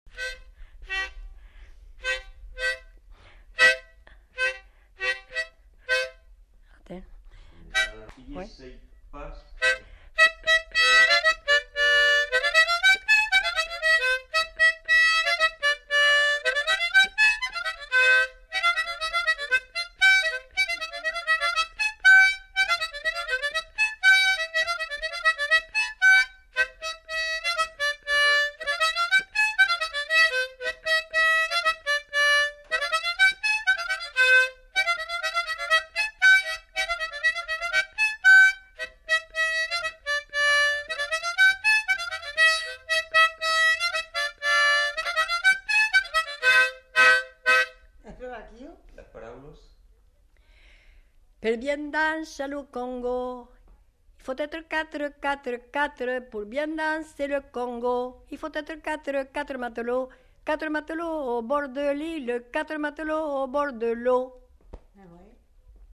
Lieu : Cancon
Genre : morceau instrumental
Instrument de musique : harmonica
Danse : congo
Notes consultables : L'interprète chante une fois les paroles.